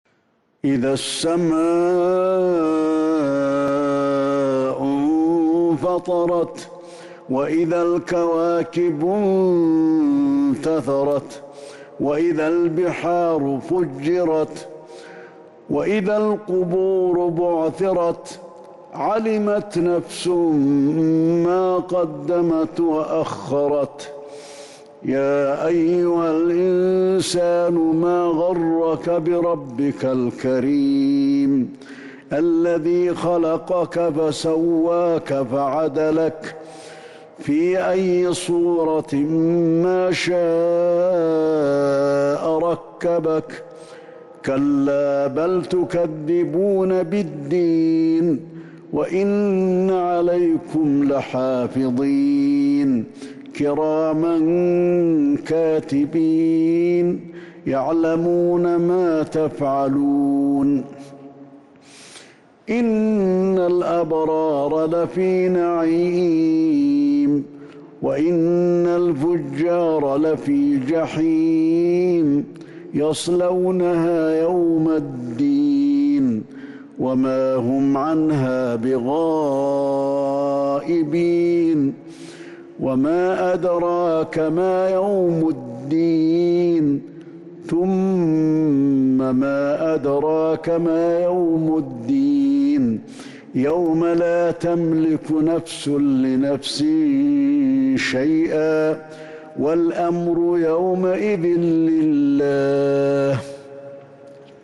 سورة الإنفطار Surat Al-Infitar من تراويح المسجد النبوي 1442هـ > مصحف تراويح الحرم النبوي عام ١٤٤٢ > المصحف - تلاوات الحرمين